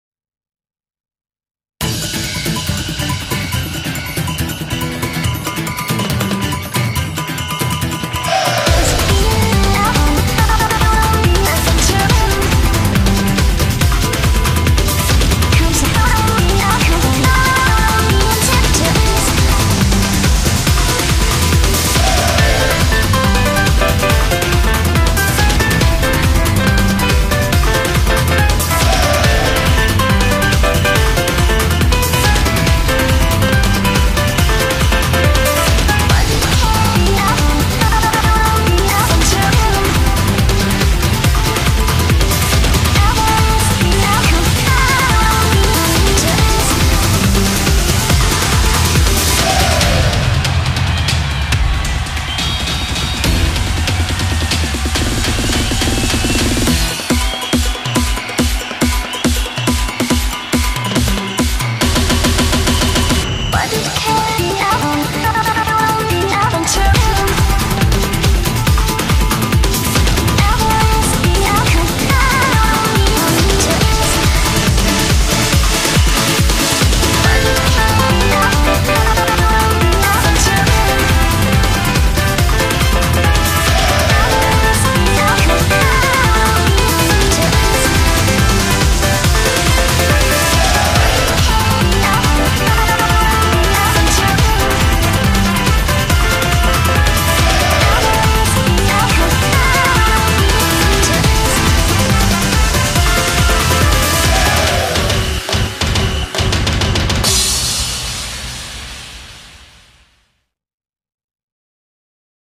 BPM140